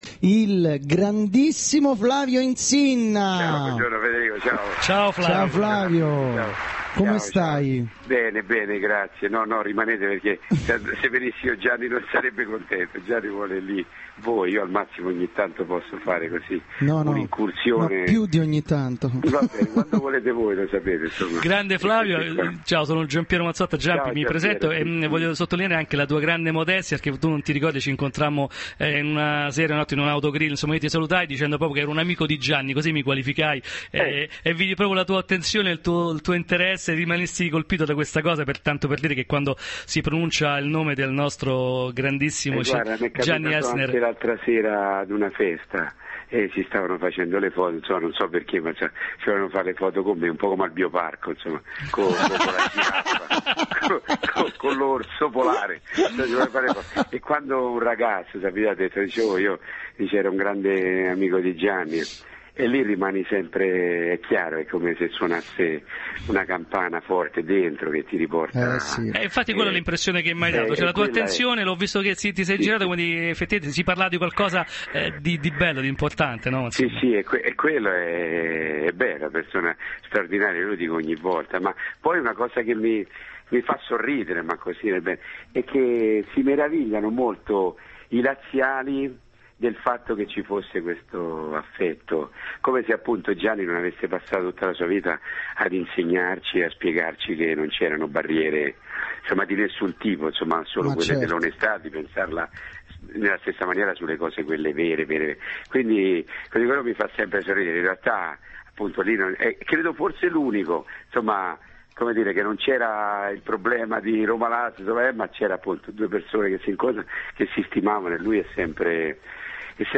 Intervento telefonico Flavio Insinna